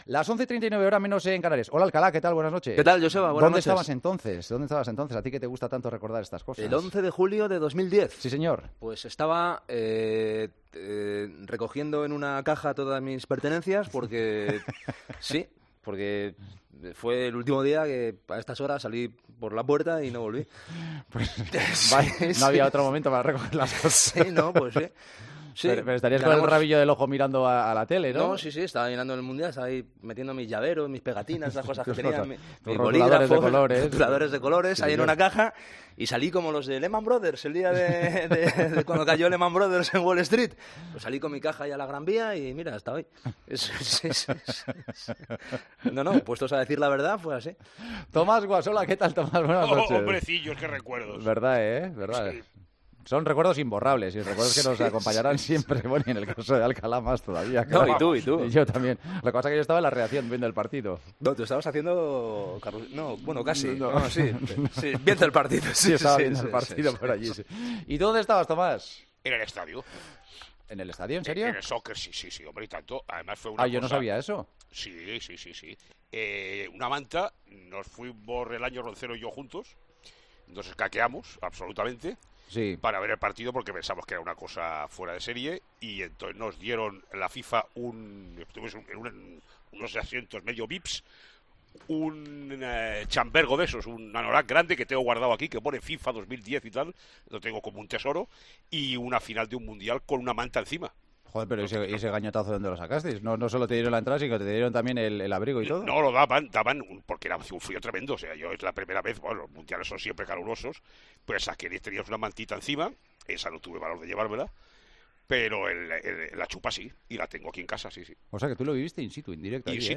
Los contertulios de 'El Partidazo' recuerdan 9 años después el triunfo de España en Sudáfrica y el gol que cambió la historia del fútbol español.